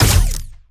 Added more sound effects.
GUNAuto_Plasmid Machinegun Single_04_SFRMS_SCIWPNS.wav